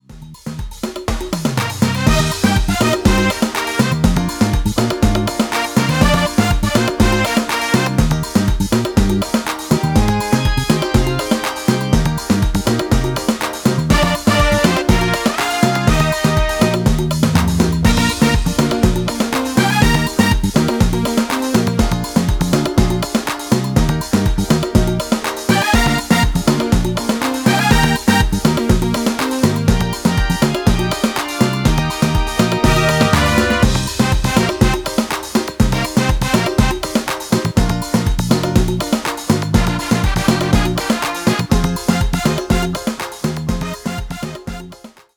Calypso Midi File Backing Tracks.